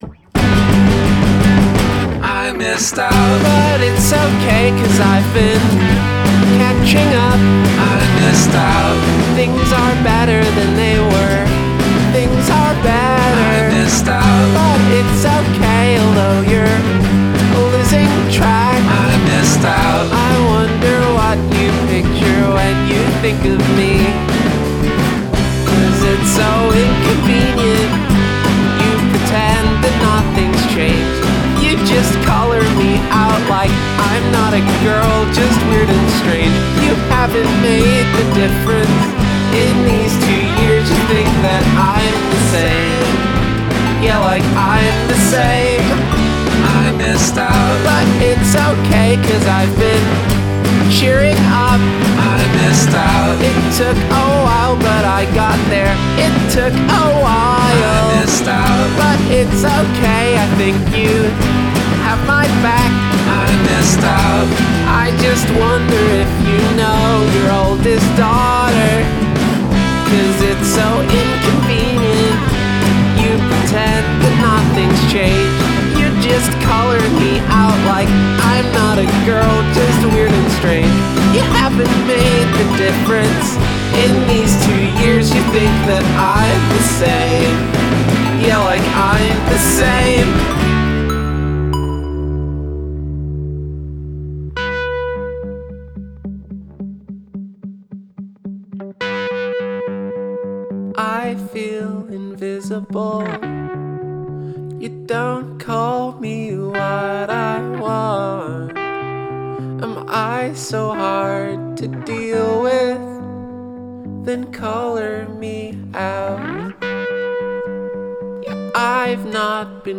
Elements of chiptune/ computer sounds immune